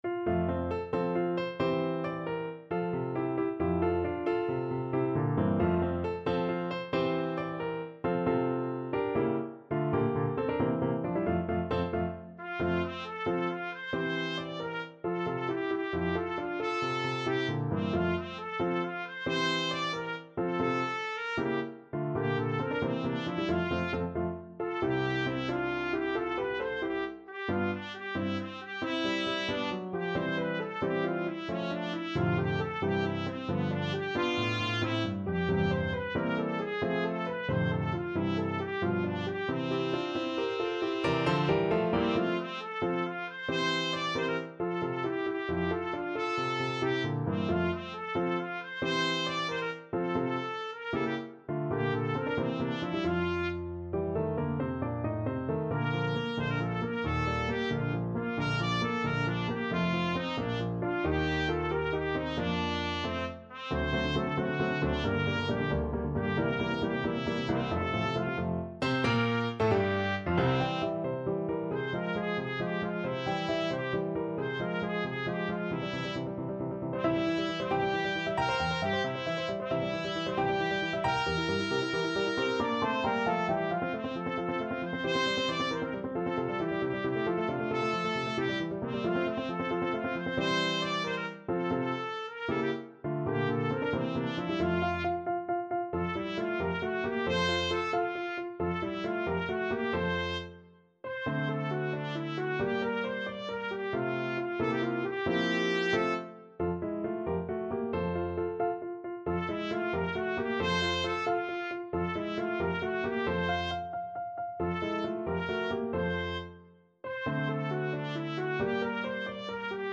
. = 90 Allegretto vivace
F major (Sounding Pitch) G major (Trumpet in Bb) (View more F major Music for Trumpet )
6/8 (View more 6/8 Music)
Trumpet  (View more Intermediate Trumpet Music)
Classical (View more Classical Trumpet Music)
cosi_amore_ladroncello_TPT.mp3